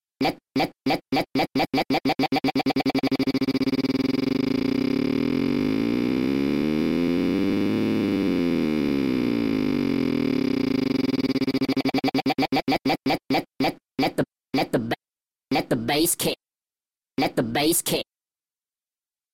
This will cause the pulse sent out by the LFO to trigger the sample to play repeatedly.
Try changing the speed on the LFO on the Subtractor – higher speeds can give some great glitchy effects. You may have to adjust the root key control on your sampler to get the pitch of the playback correct, but in this case I actually liked the sound of the sample slightly pitched up.